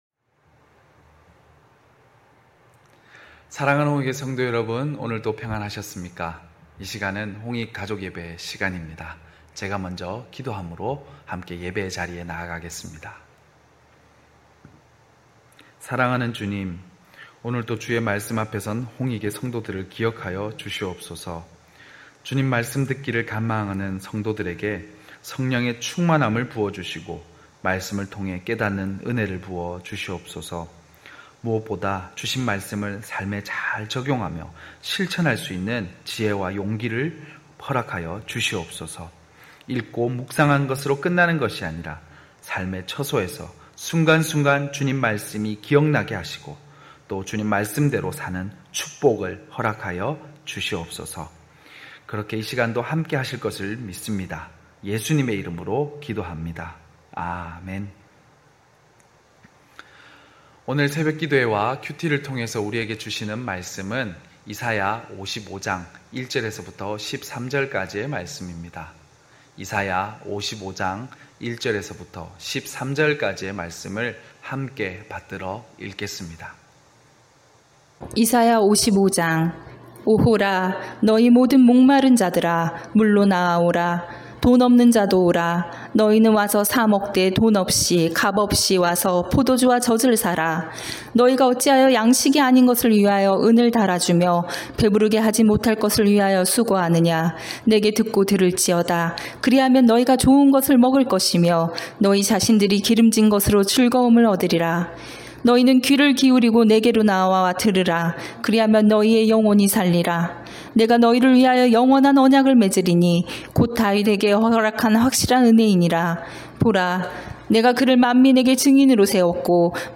9시홍익가족예배(8월3일).mp3